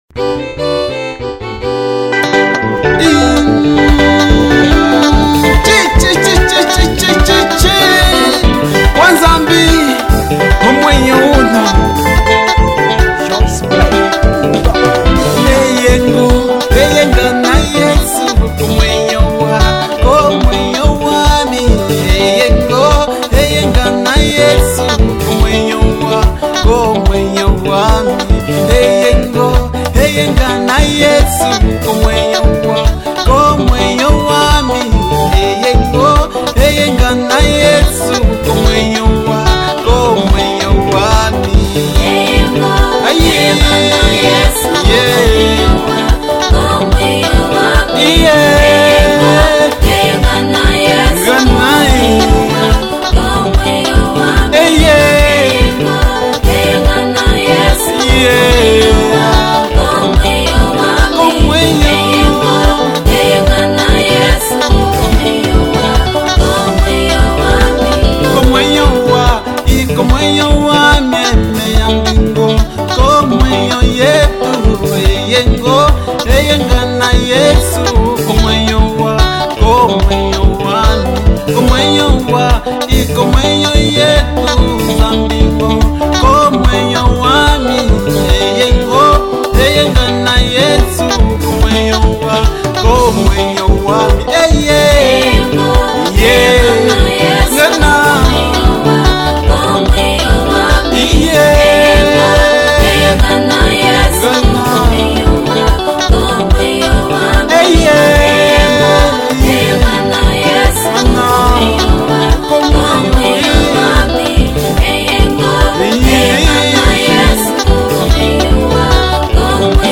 Género: Gospel